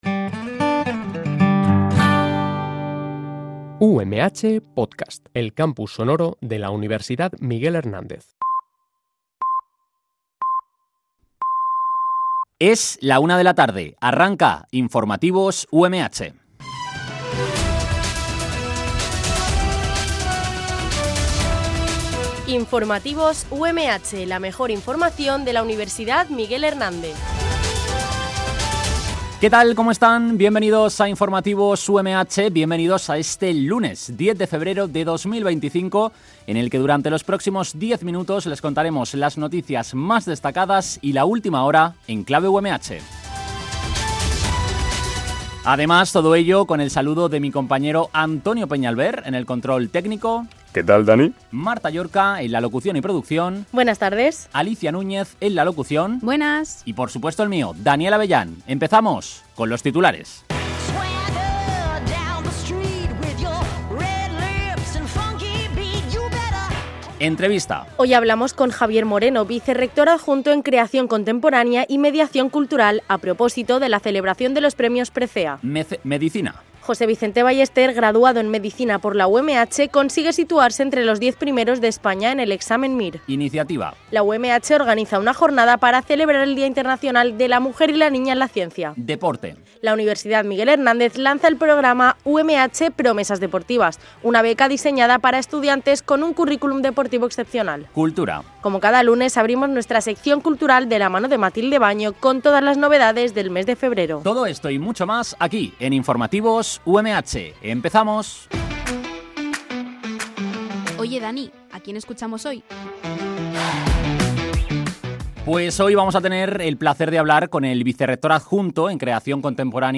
Este programa de noticias se emite de lunes a viernes